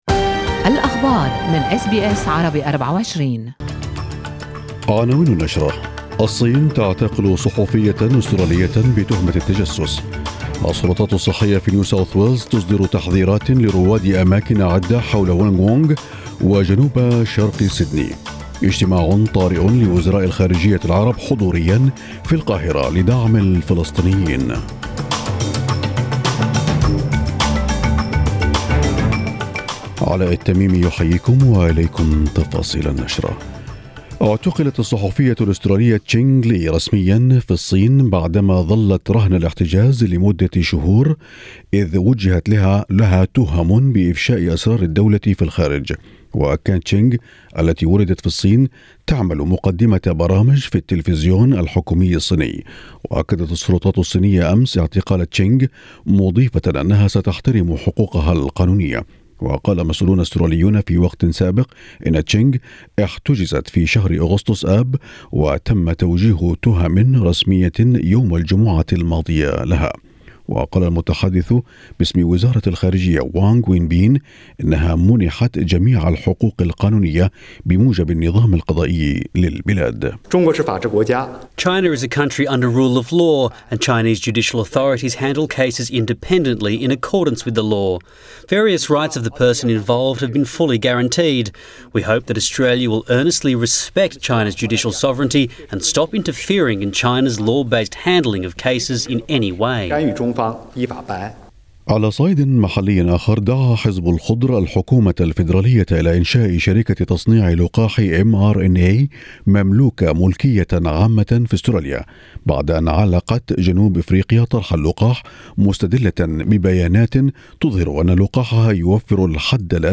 نشرة أخبار الصباح 9/2/2021